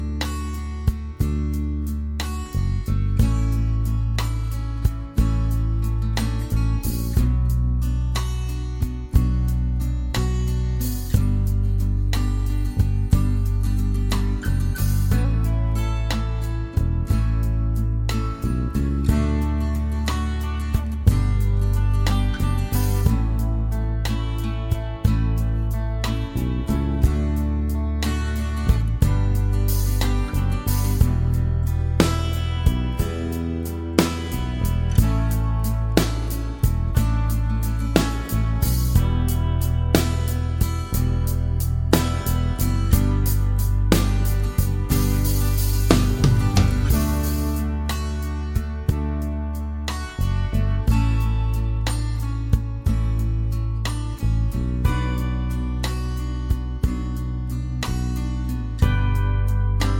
No Harmonica Country (Male) 5:30 Buy £1.50